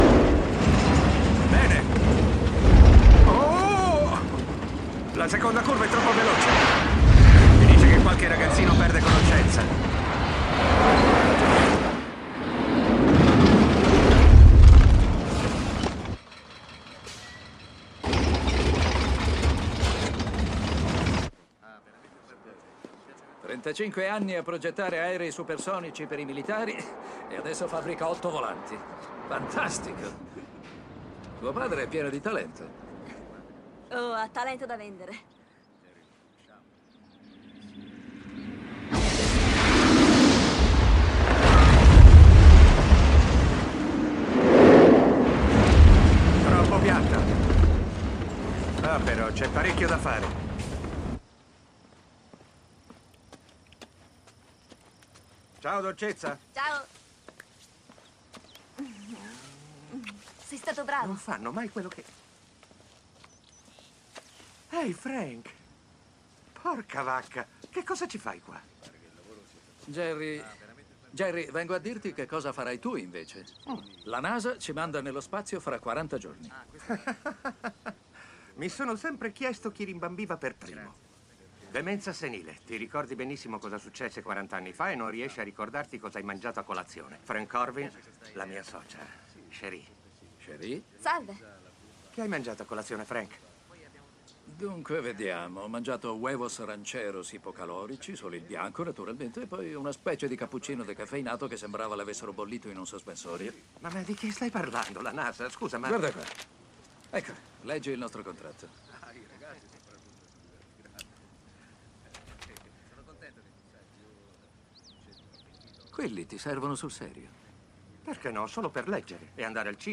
nel film "Space Cowboys", in cui doppia Donald Sutherland.